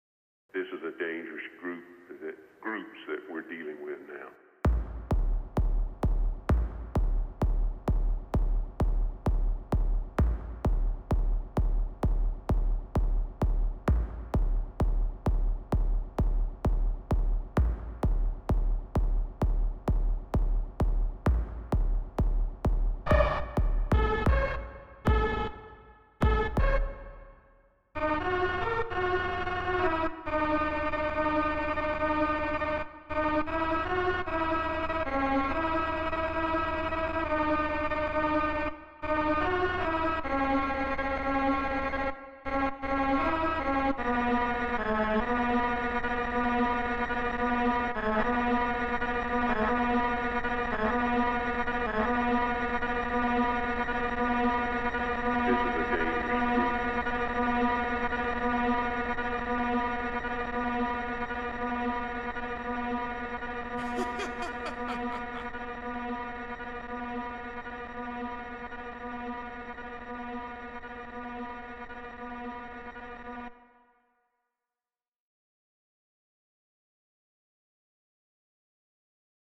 weird, experimental, glitch,